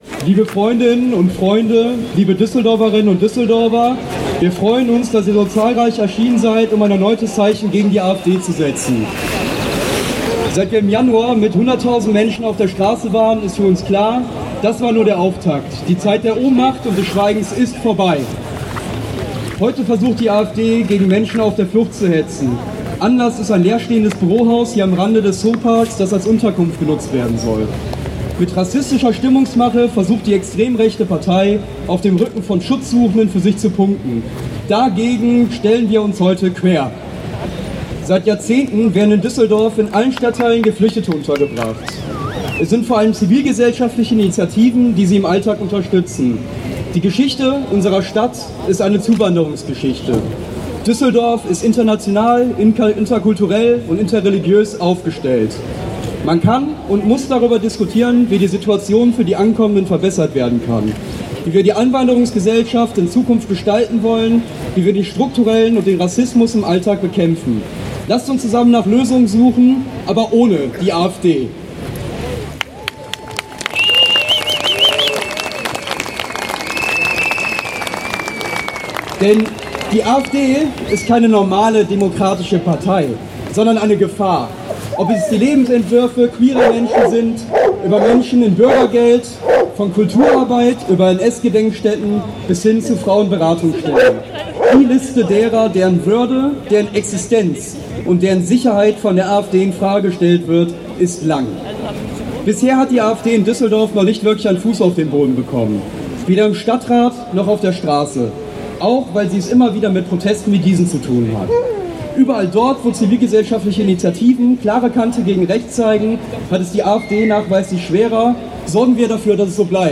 Kundgebung „Düsseldorf stellt sich quer gegen extreme Rechte und Rassismus!“ (Audio 2/7)